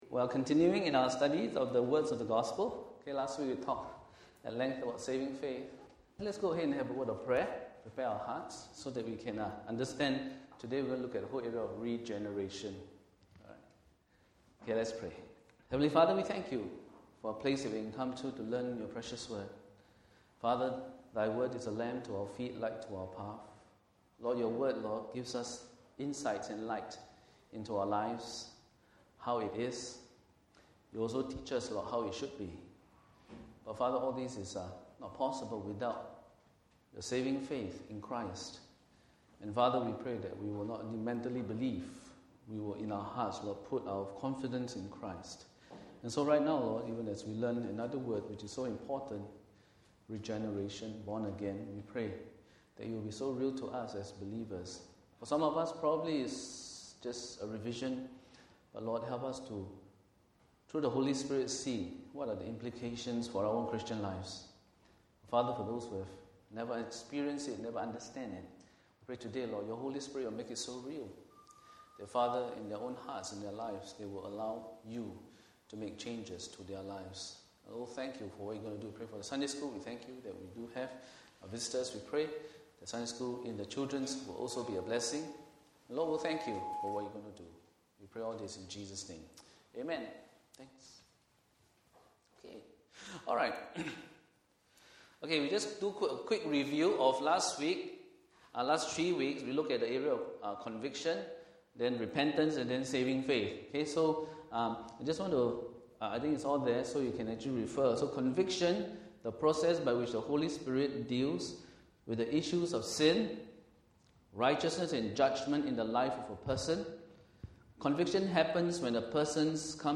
Join us for a hearty discussion regarding the Words of The Gospel; Conviction, Repentance and Saving Faith !